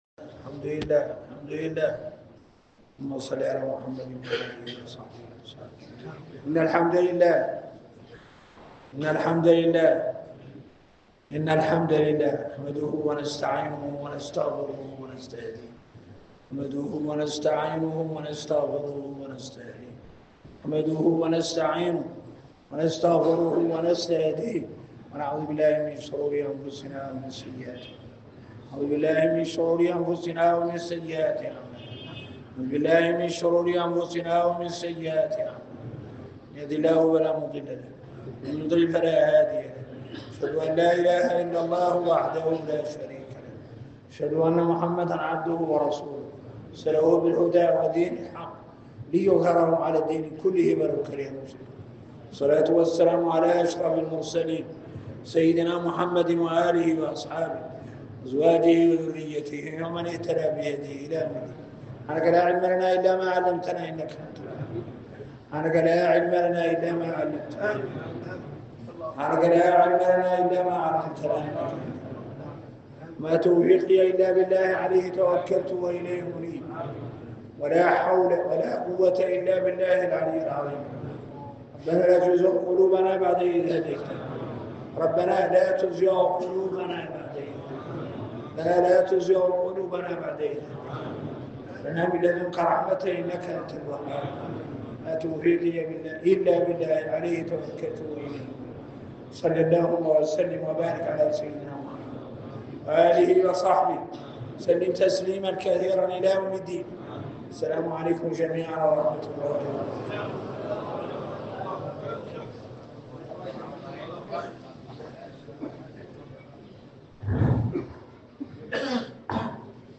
محاضرة علمية